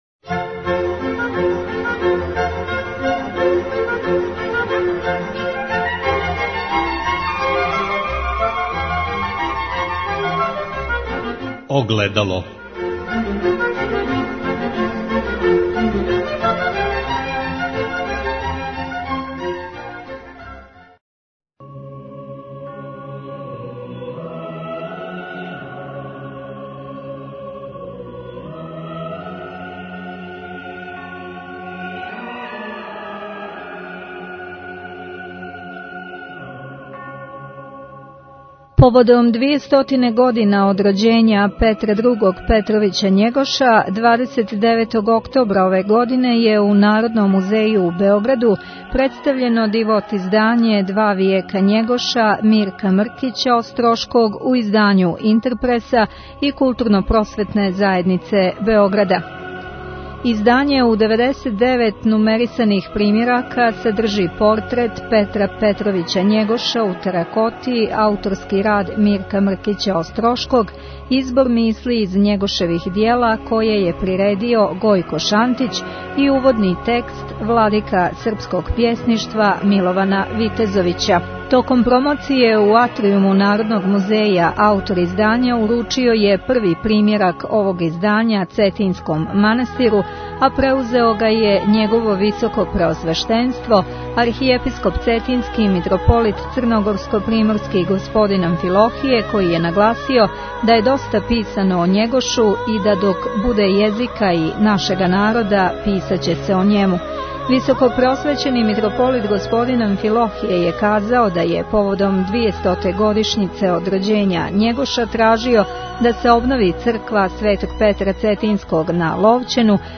Download the file . 21:36 минута (3.71 МБ) Поводом 200 година од рођења Петра II Петровића Његоша јуче је у Народном музеју у Београду представљено дивот-издање "Два вијека Његоша" Мирка Мркића Острошког у издању Интерпреса и Културно-просветне заједнице Београда.